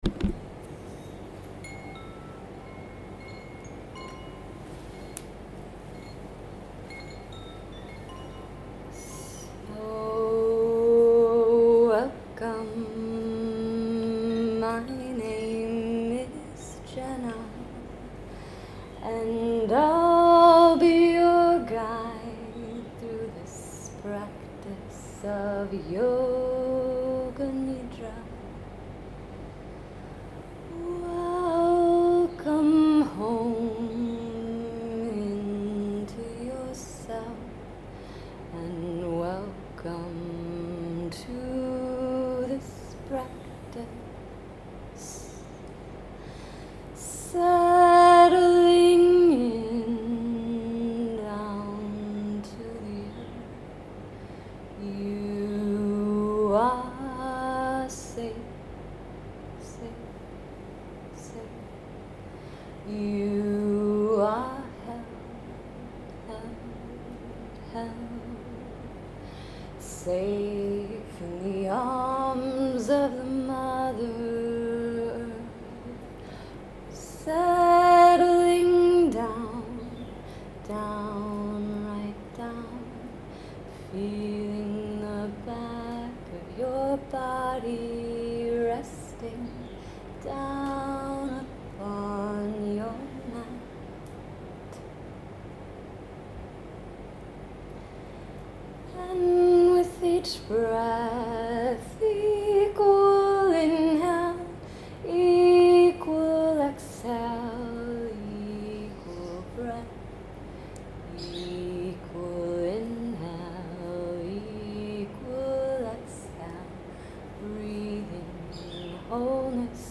Vocal Mode: Sung (in whole or in part)
This 15-miniute yoga nidrā was delivered in song form from start to finish with the expressed intention to awaken the soul song of the listener. It utilized a combination of iRest and Satyananda style rotations.